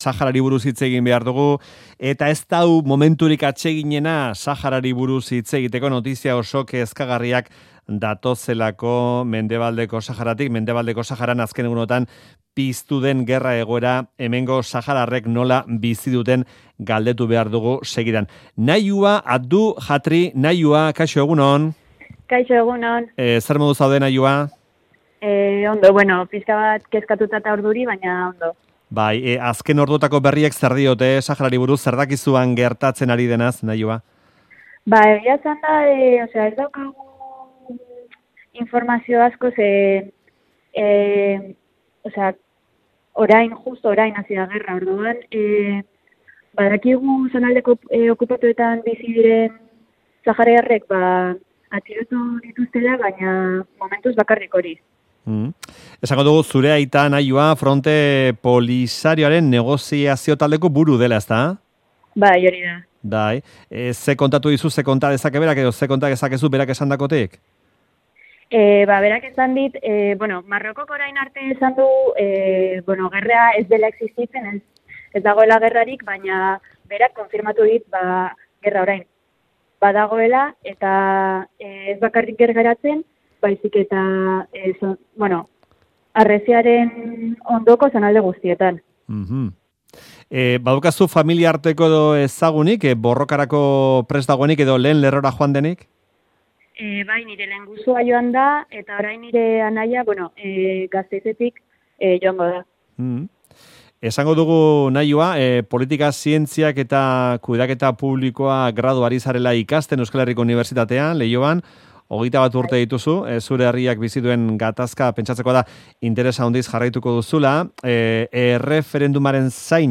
Audioa: 20 eta 21 urteko saharar jatorriko bi neska gazte euskaldunekin aritu gara Mendebaldeko Sahararen eta Marokoren artean piztu den gerra egoeraz, gatazkaz